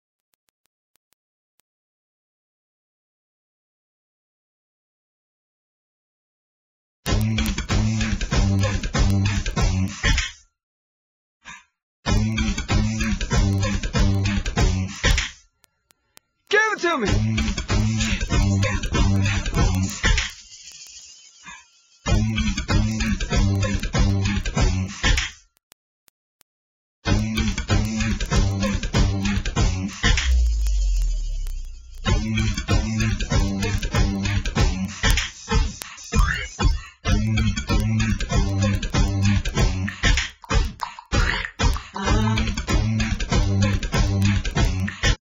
NOTE: Vocal Tracks 1 Thru 8